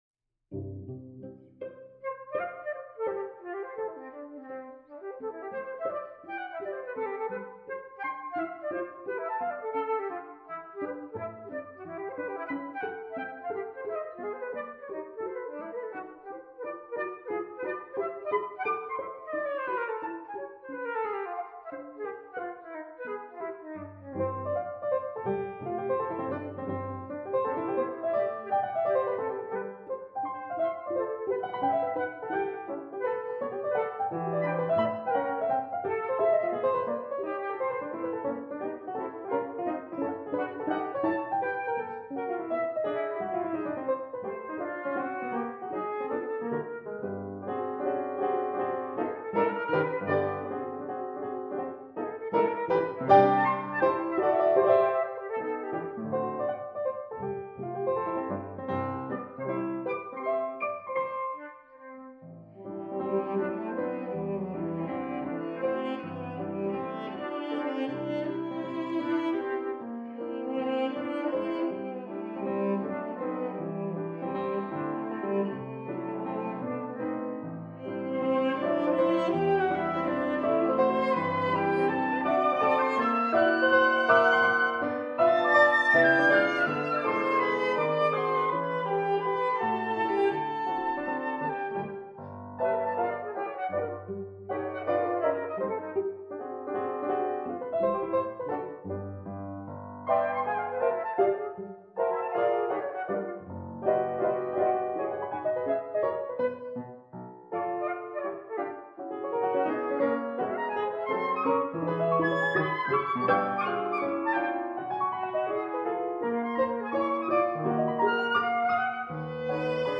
flute
cello
piano